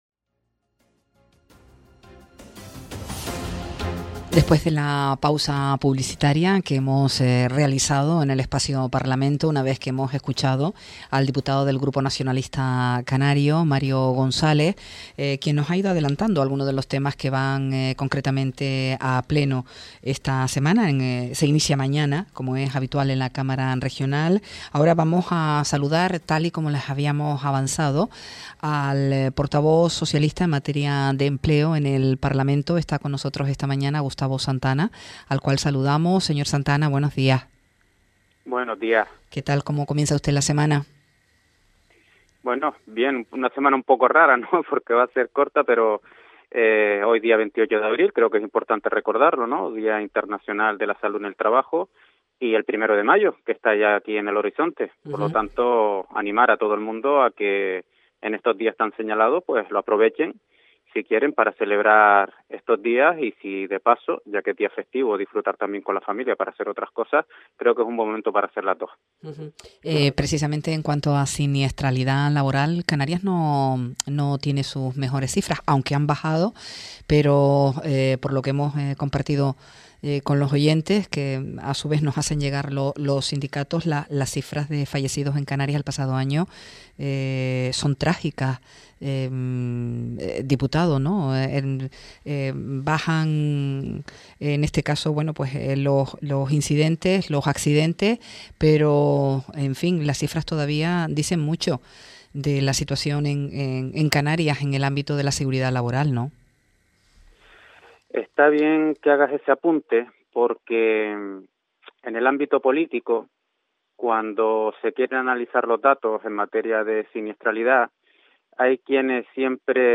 Gustavo Santana, diputado del Grupo Socialista y portavoz en materia de Empleo en la Cámara regional, interviene esta mañana en el espacio “Parlamento”.